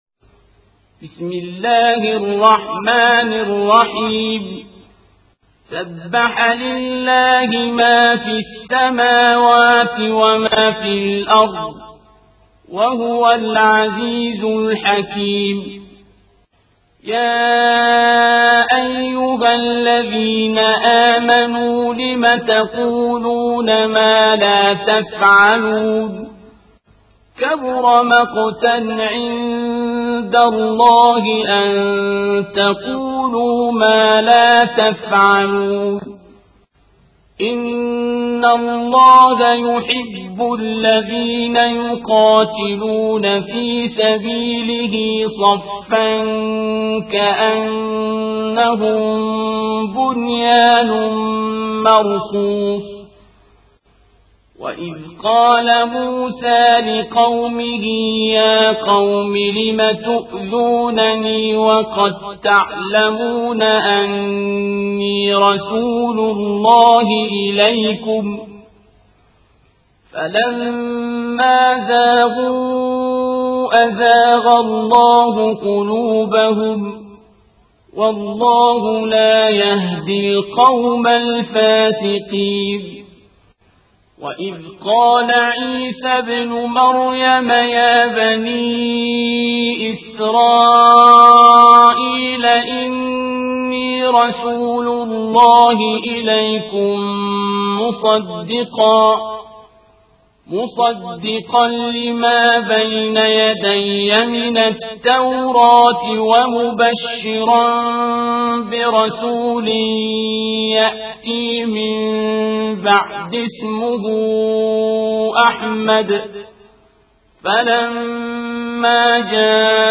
القارئ: الشيخ عبدالباسط عبدالصمد